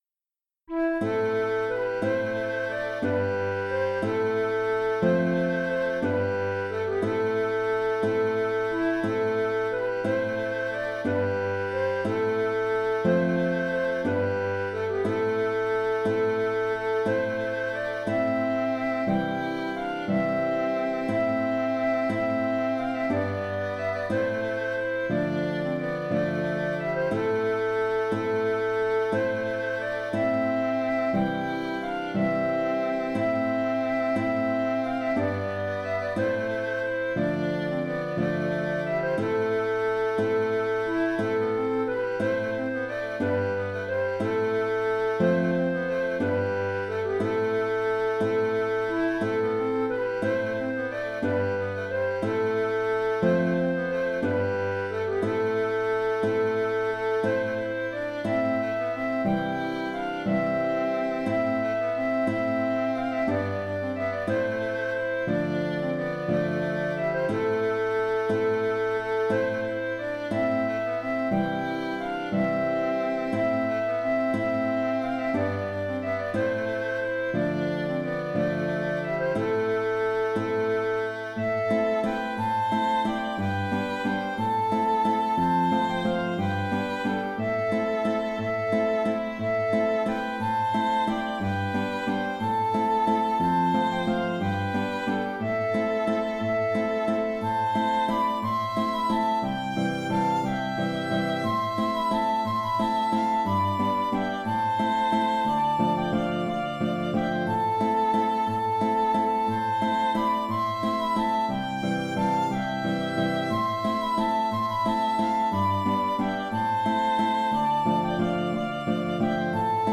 Marv Pontkallek (Chant) - Musique bretonne
Evidemment, ce chant n’invite pas à la danse.
Auteur : Gwerz traditionnelle bretonne.